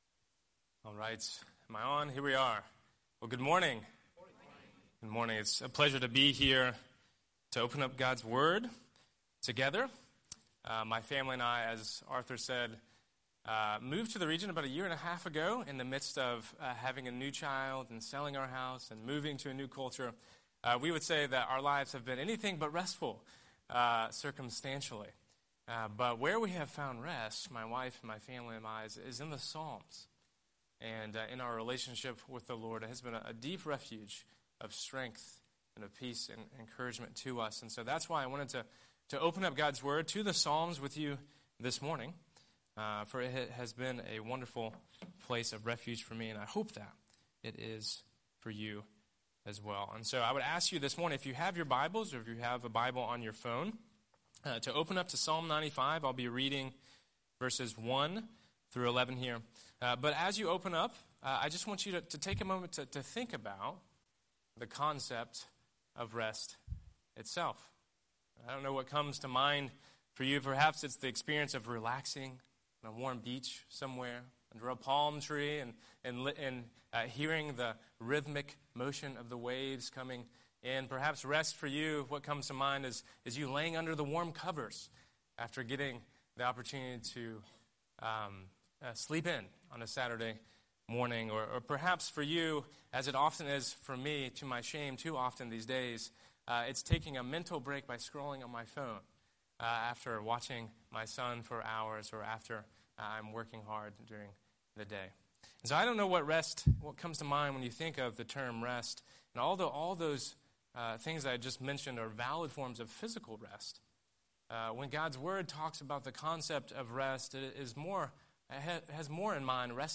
Scripture: Psalm 95:1–11 Series: Sunday Sermon